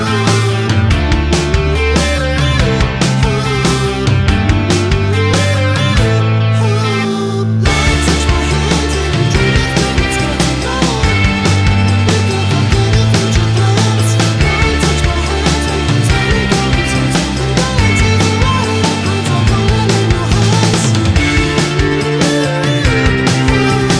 no Backing Vocals Indie / Alternative 2:48 Buy £1.50